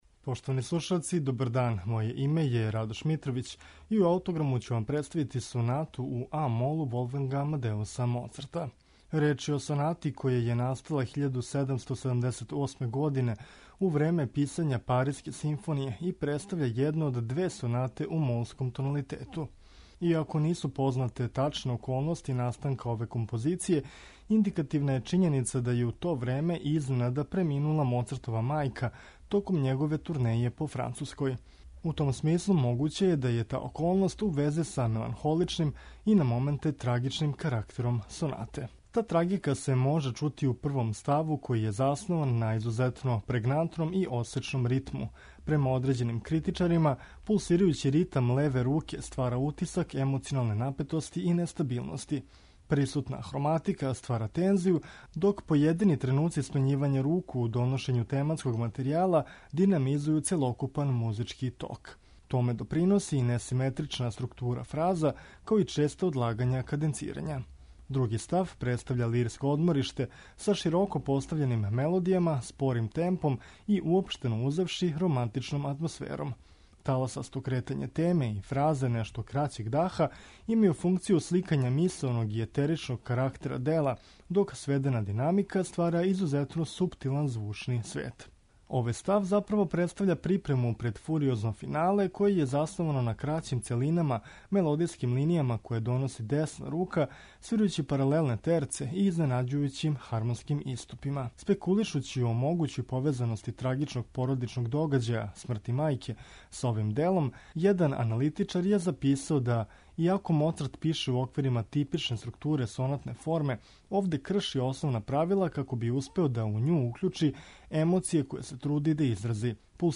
МОЦАРТ: КЛАВИРСКА СОНАТА У А-МОЛУ
Моцартову Сонату у а-молу слушаћемо у интерпретацији пијанисте Григорија Соколова.